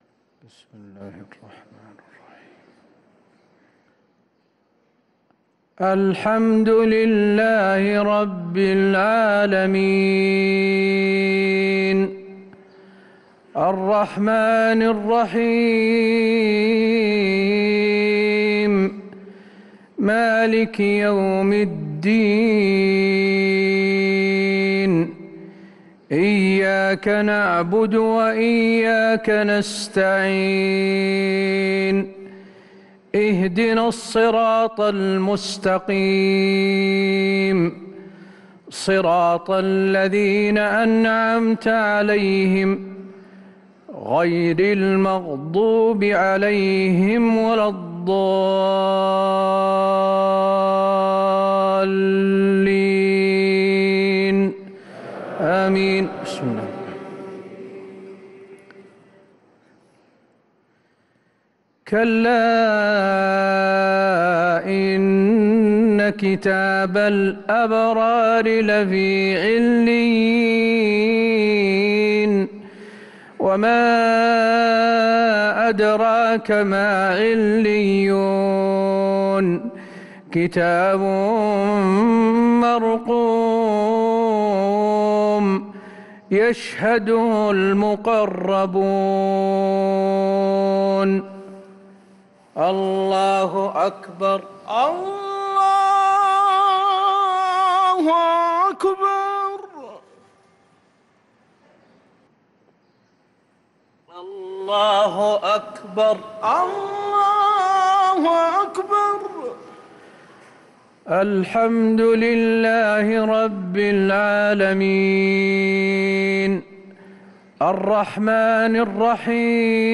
صلاة المغرب للقارئ حسين آل الشيخ 29 جمادي الأول 1445 هـ